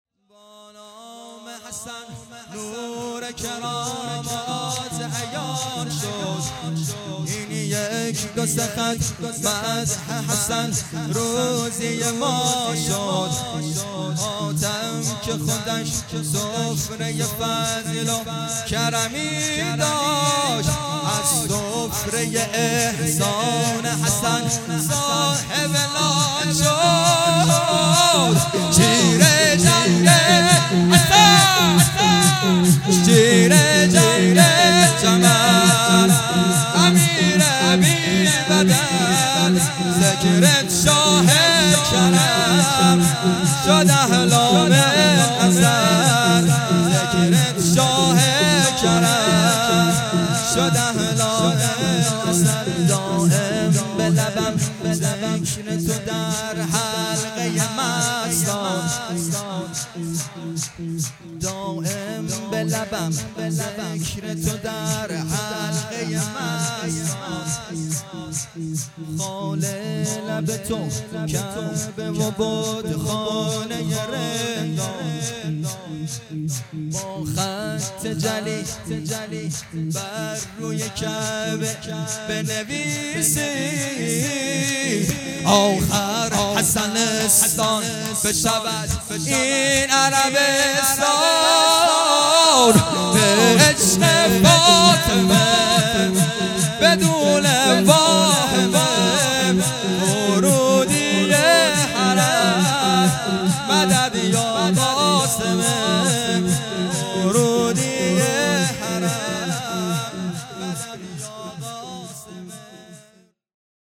مراسم جشن ولادت امام حسن مجتبی(ع) 99